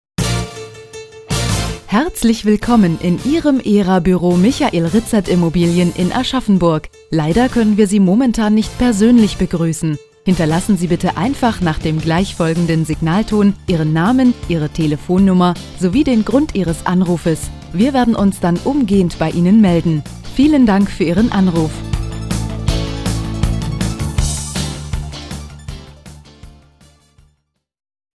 Warteschleife - Autohaus